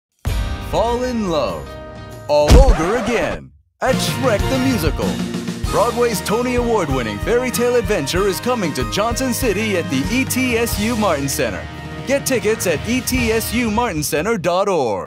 Professional American Male Voice Actor | Commercial, E-Learning & Corporate Narration
Commercial Demo
Known for a deep, authoritative voice as well as warm, conversational, and relatable reads, I provide versatile performances tailored to luxury brands, tech explainers, financial narration, medical content, network promos, political campaigns, and cinematic trailers.